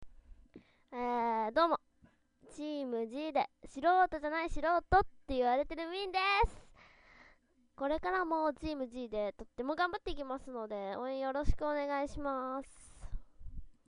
自己紹介ボイス